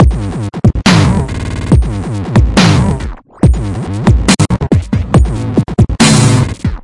Tag: Linux的 LMMS dubstep的 厚颜无耻 毛刺 断裂 效果 BPM 节拍 rb338 140 重生